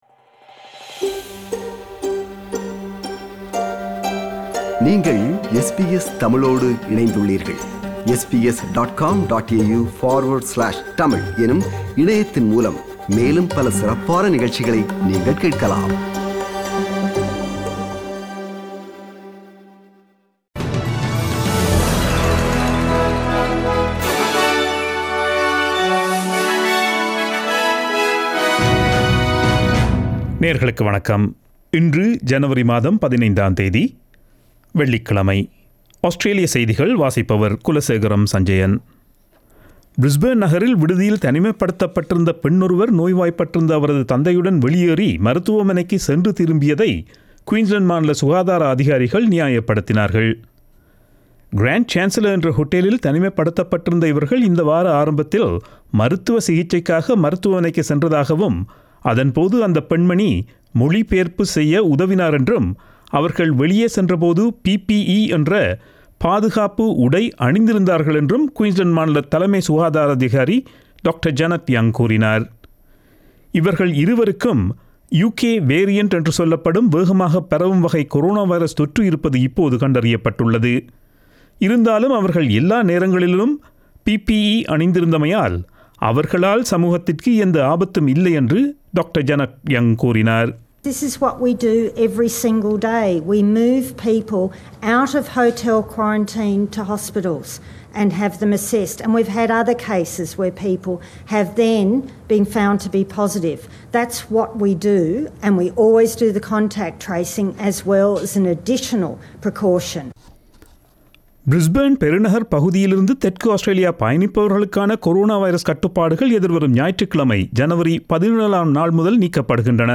Australian news bulletin for Friday 15 January 2021.